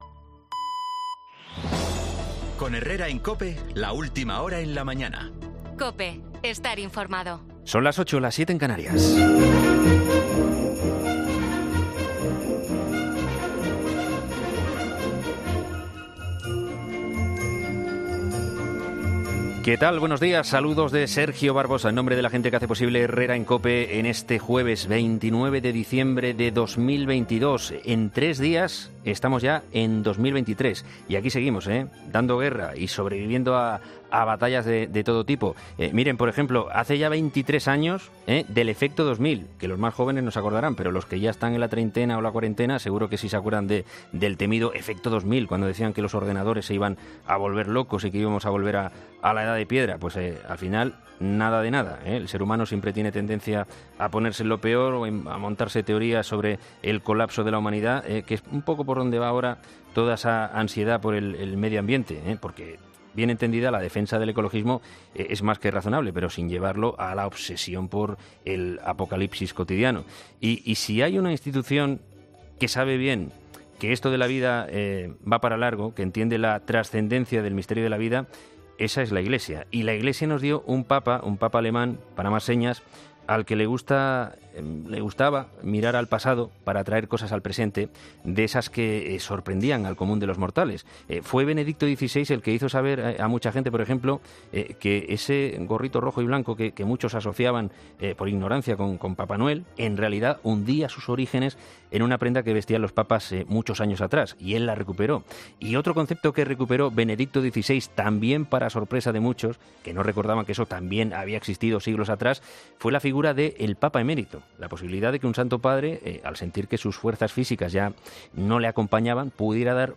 reflexiona en el monólogo de las 8